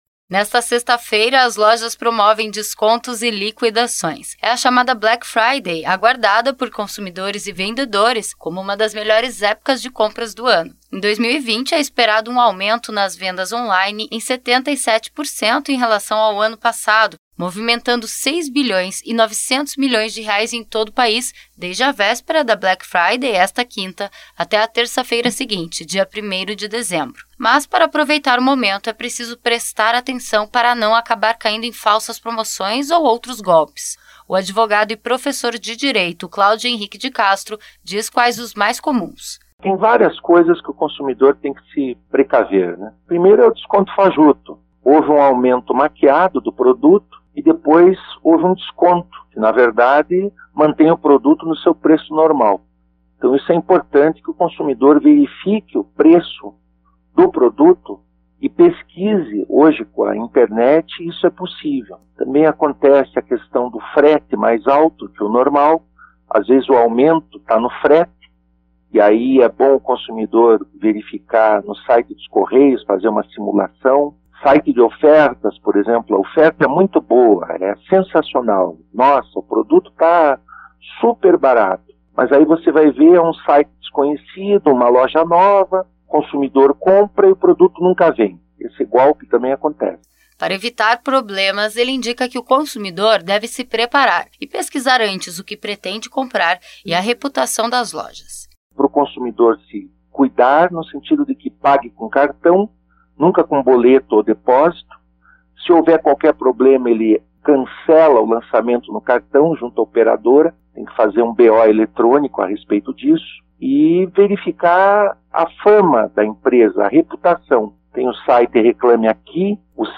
O consumidor que quer aproveitar a Black Friday deve se programar antes. Na reportagem, dicas para escolher os produtos e não cair em golpes.